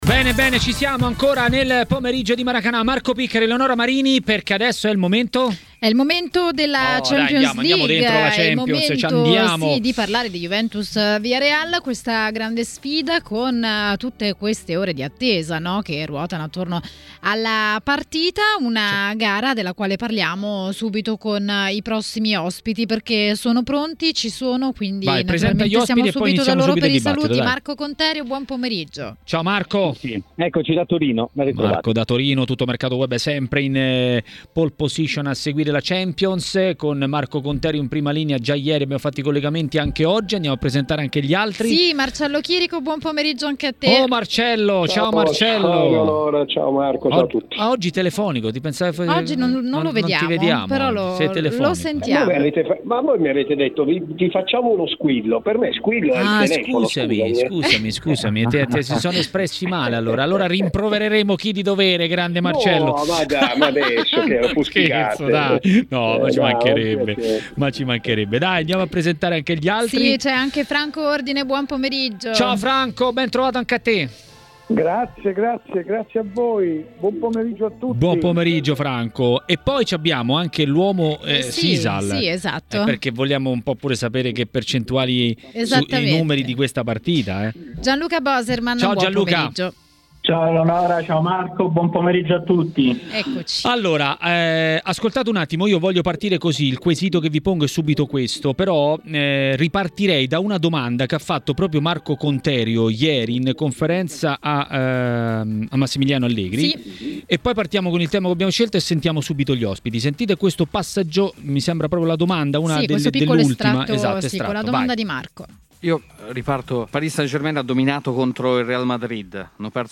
TMW Radio Regia Ascolta l'audio Ospiti